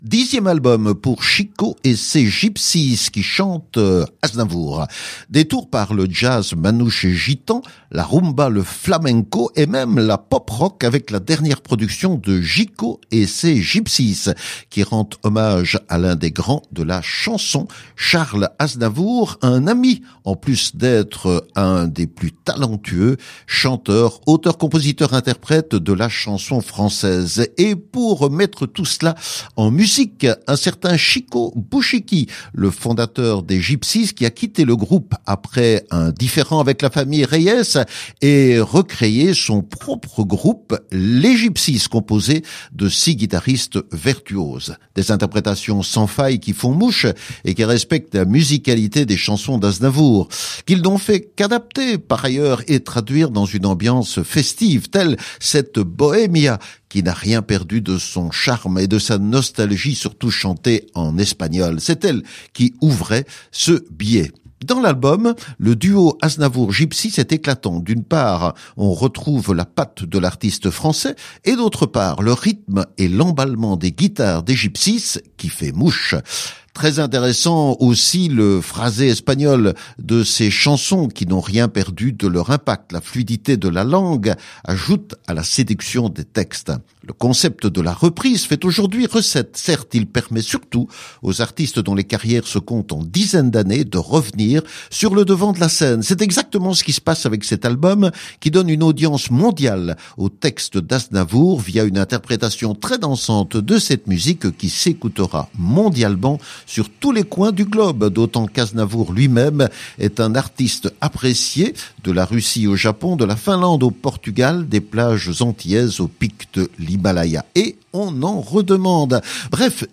groupe musical français de rumba, flamenca, pop, rock